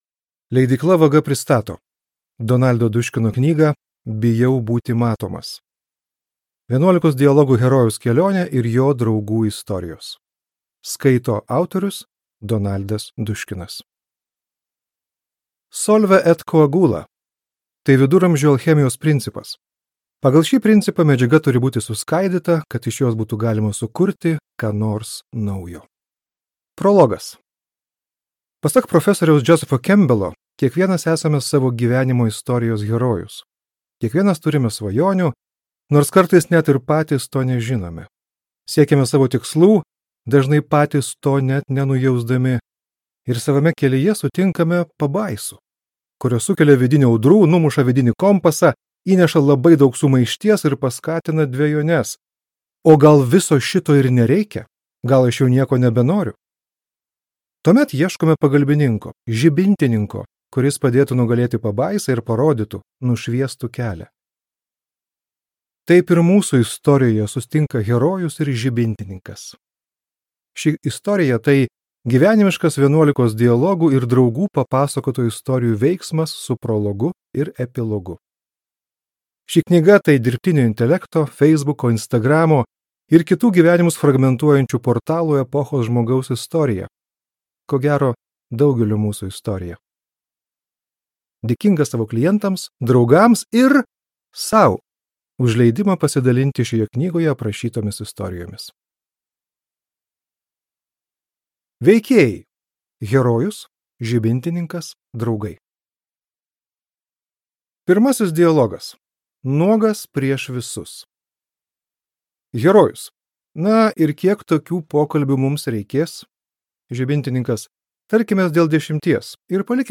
Bijau būti matomas | Audioknygos | baltos lankos